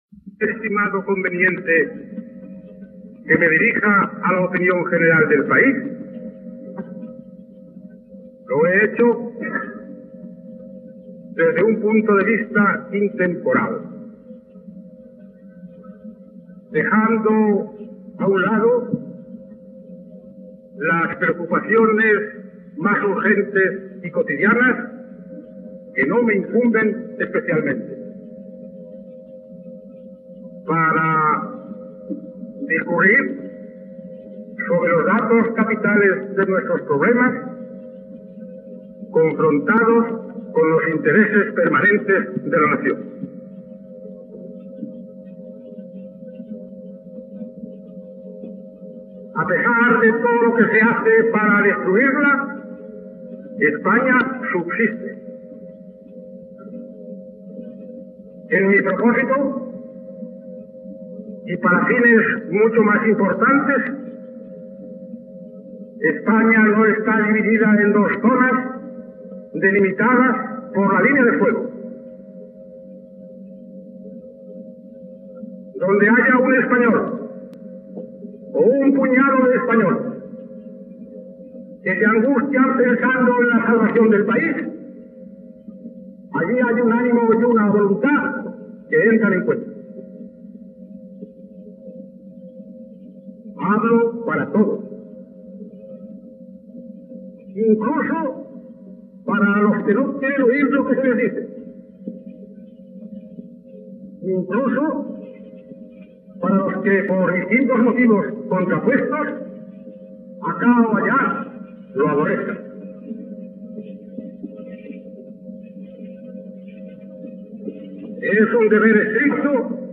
Últim discurs del president de la II República espanyola Manuel Azaña, pronunciat a l'Ajuntament de Barcelona.
Primera part del discurs.